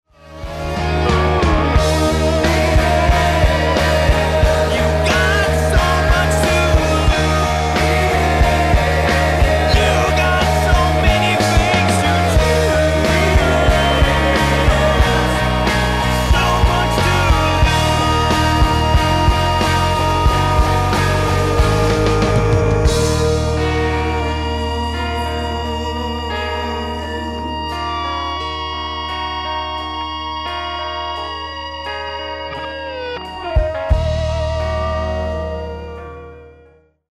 NO BULLSHIT ROCK & ROLL!